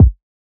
interstellar kick.wav